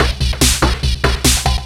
DS 144-BPM A4.wav